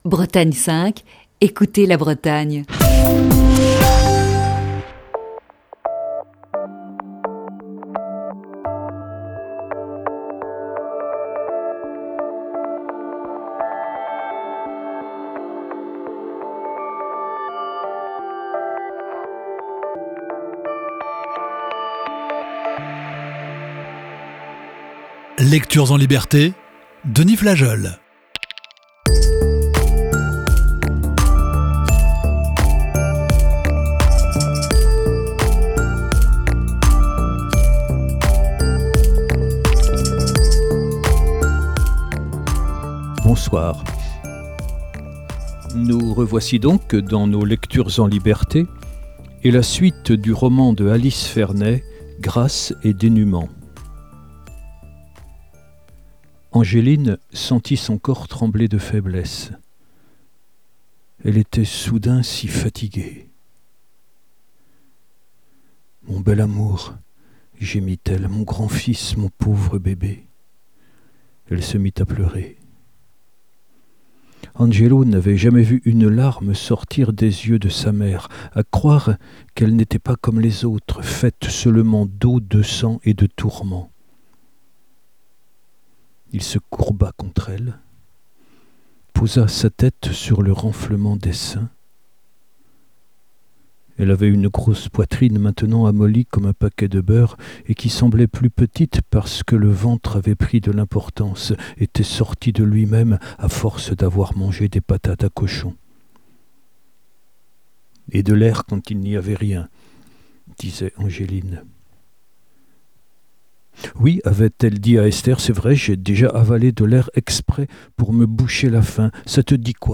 Émission du 11 février 2021.